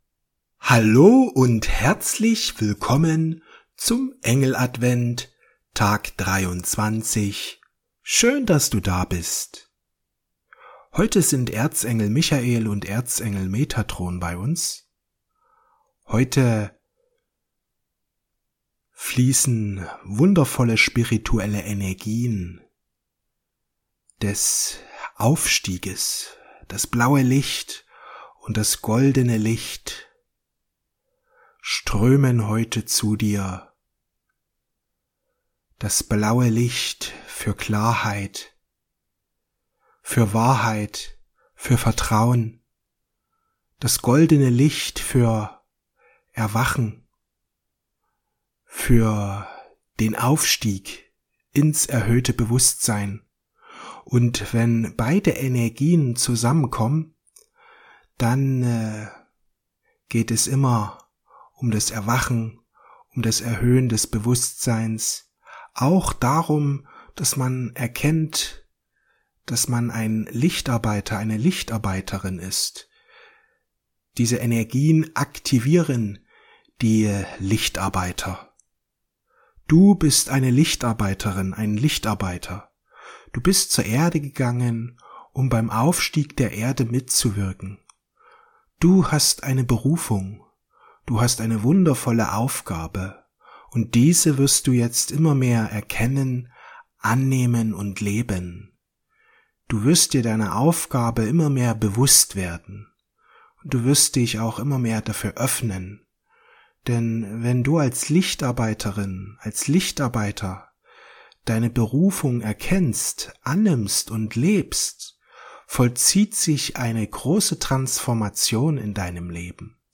Die gold-blaue Frequenz des erhöhten Bewusstseins Meditation mit Erzengel Michael und Erzengel Metatron